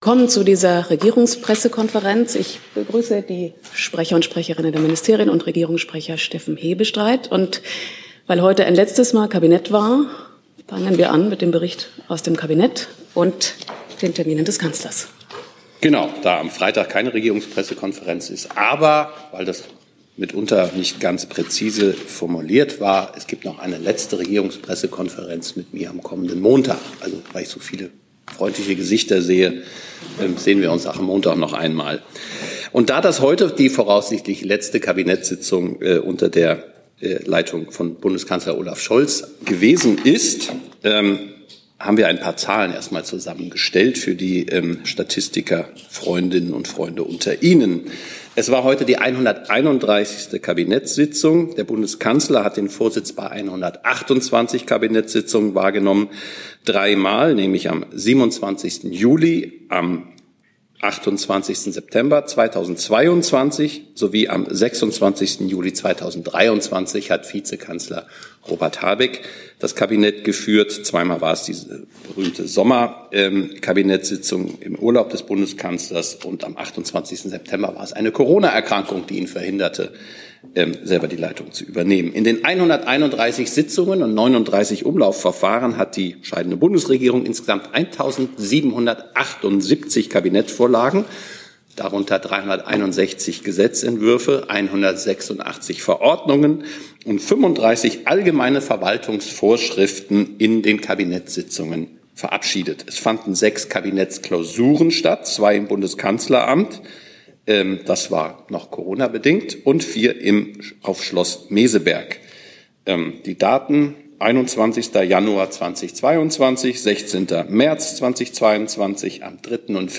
Komplette Regierungspressekonferenzen (RegPK) und andere Pressekonferenzen (BPK) aus dem Saal der Bundespressekonferenz.